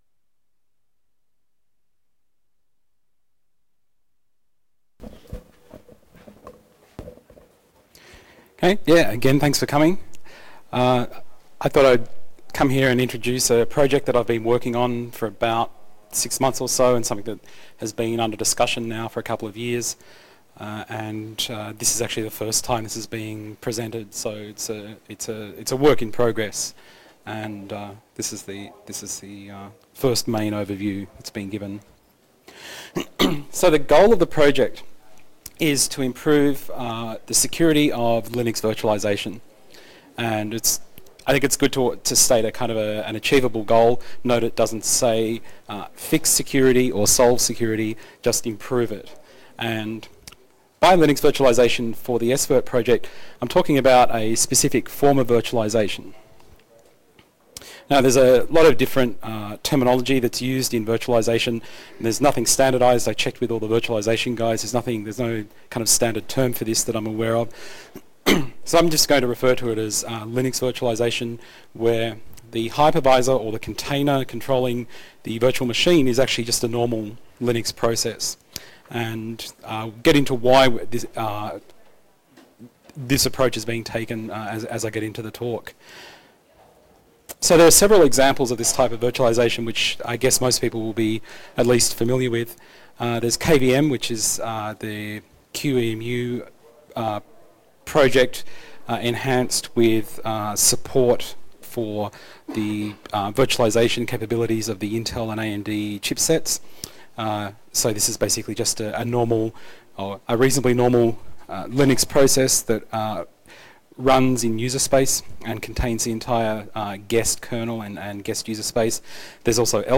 lca-2009-svirt-talk-video.ogg